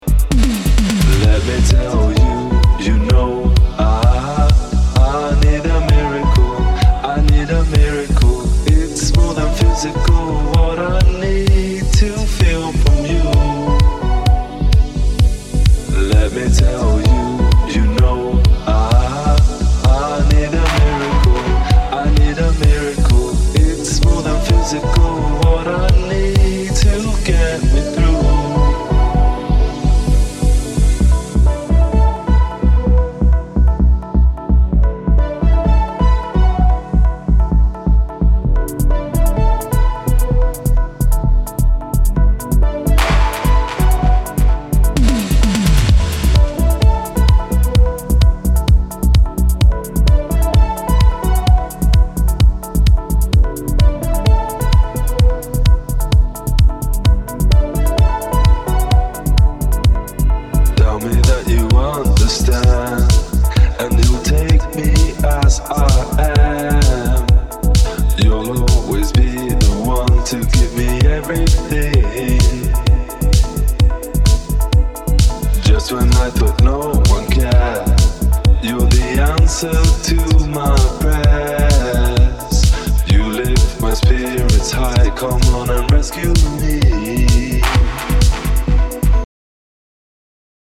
Club Version